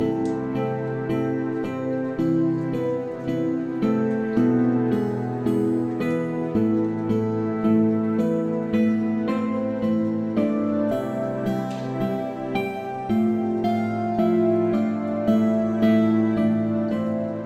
悲伤的吉他循环 Trap或Lo Fi循环
描述：A小调 110BPM